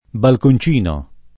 balconcino [ balkon ©& no ] s. m.